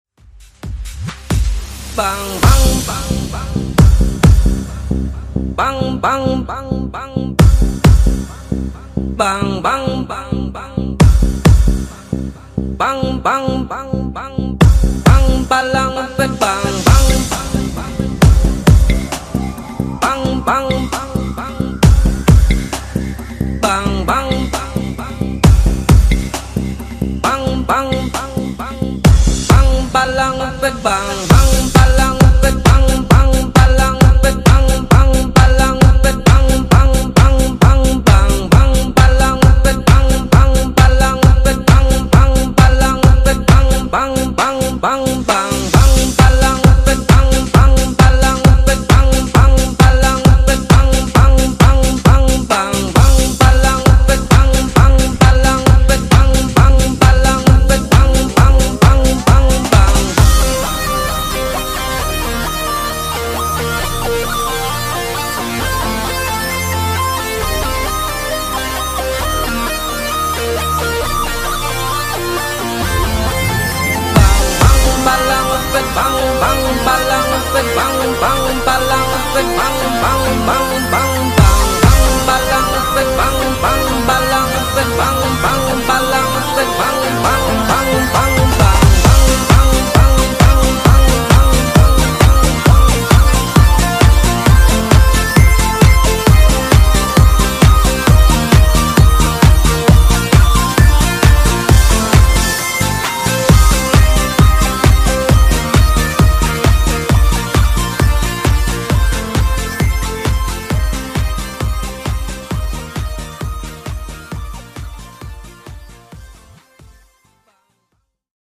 Genres: RE-DRUM , REGGAETON
Clean BPM: 80 Time